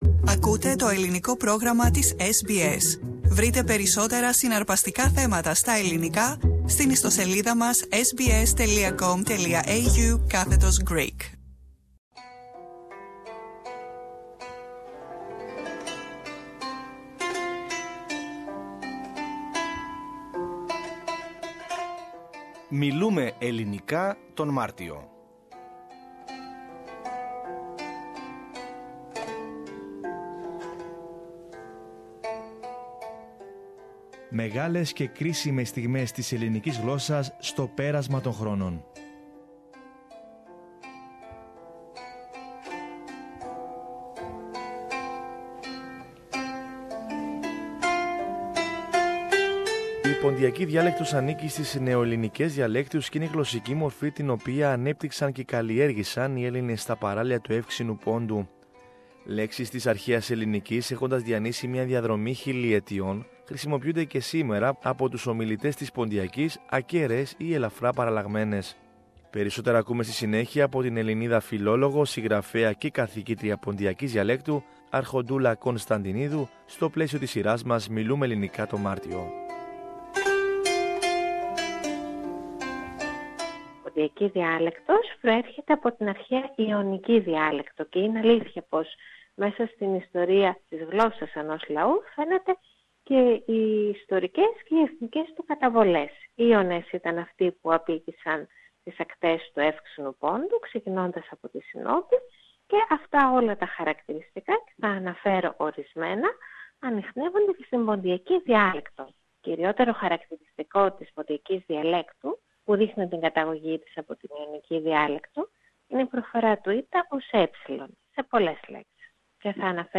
συζήτηση